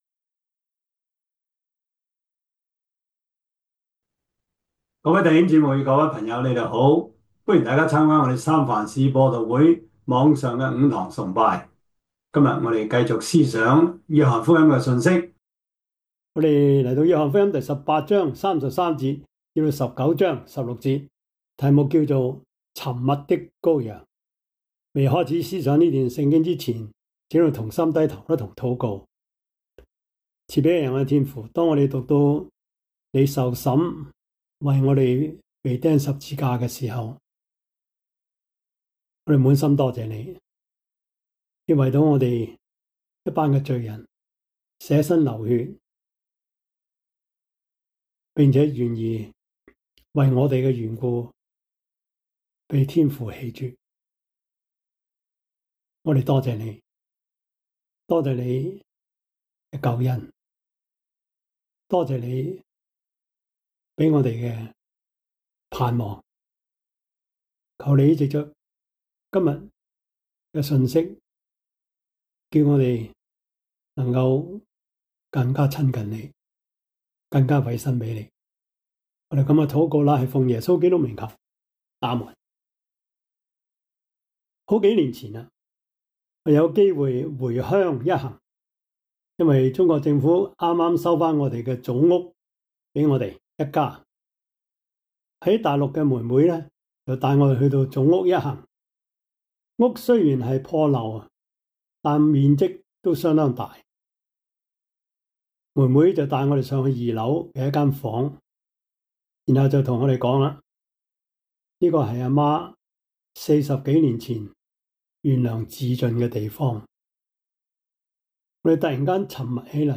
約翰福音 18:33-9:16 Service Type: 主日崇拜 約翰福音 18:33-19:16 Chinese Union Version
Topics: 主日證道 « 光明之子 第九十二課: 天國與政治 – 第二十九講 天國=離地的福音?